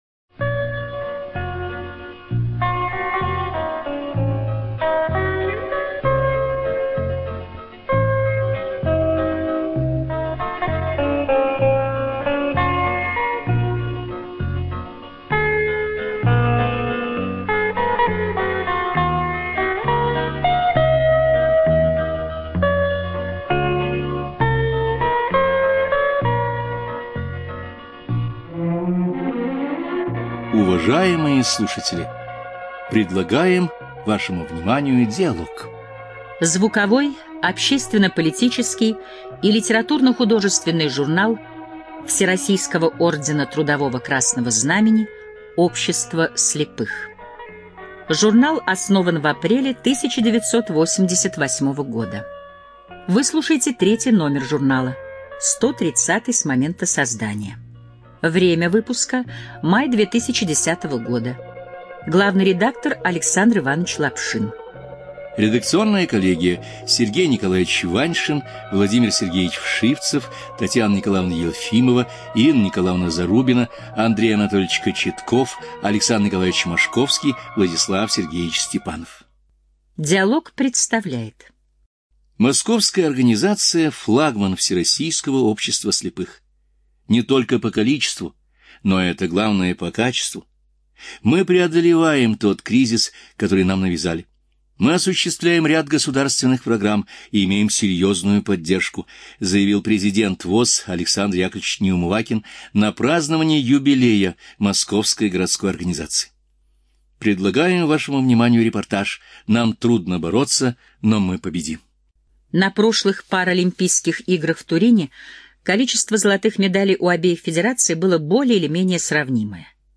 ЖанрРеабилитация, Публицистика, Документальные фонограммы
Студия звукозаписиЛогосвос